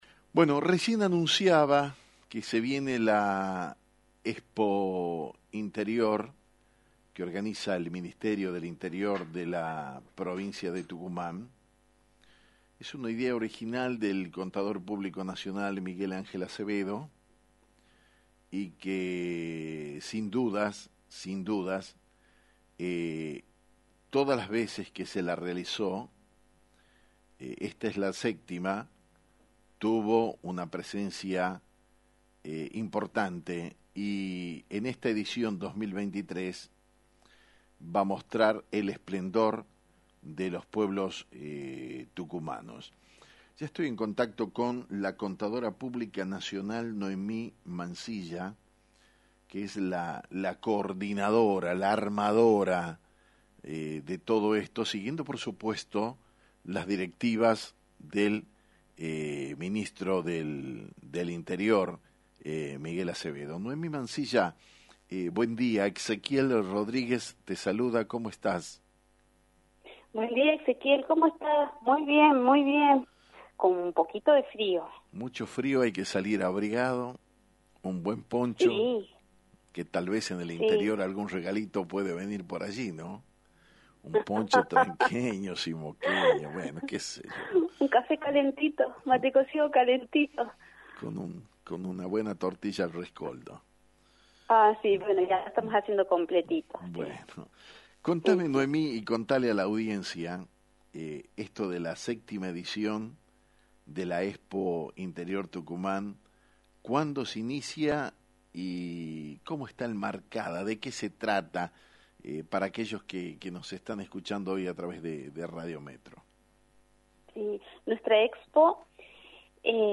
En diálogo con Actualidad en Metro